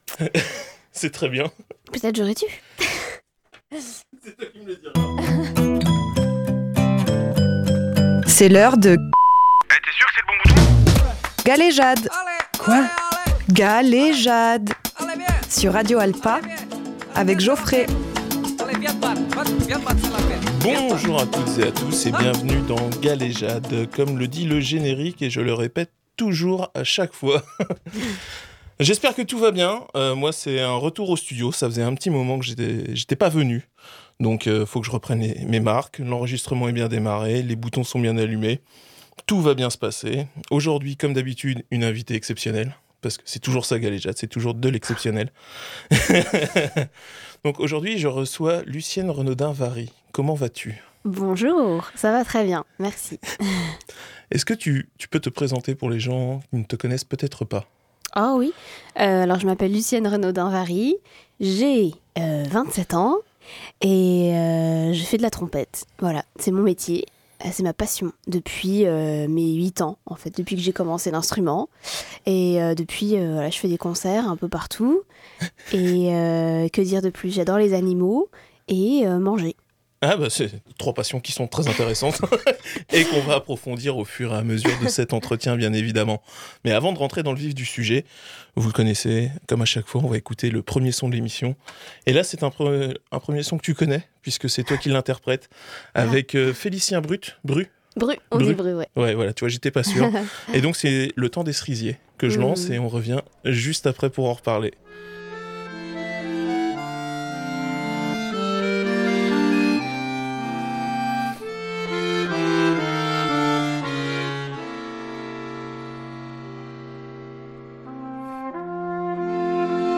Avec la trompettiste Lucienne Renaudin Vary
Retour en studio avec ma nouvelle invitée qui me fait le plaisir de venir dans l’émission pour parler de son parcours, sa passion et son actualité.